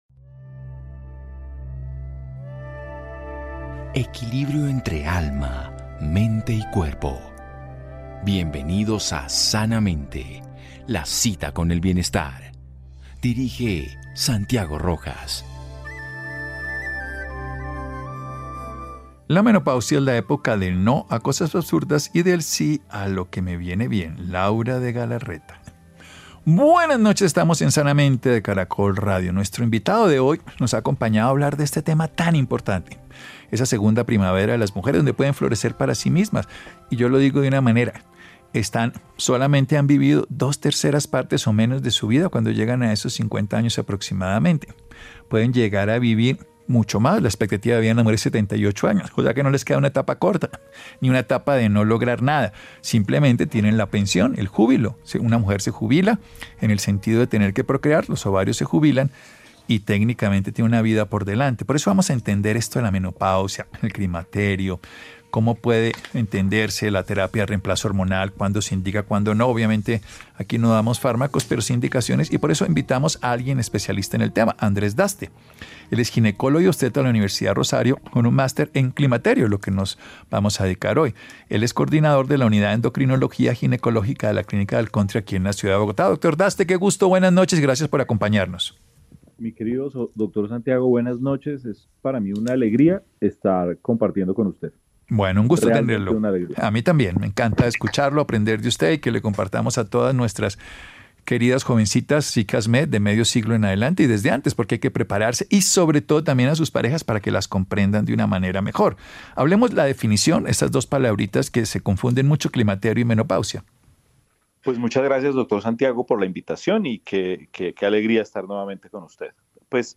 Hablamos con un médico especialista en menopausia para aclarar dudas y brindar recomendaciones a todas las mujeres para vivir en plenitud una época que trae cambios y oportunidades.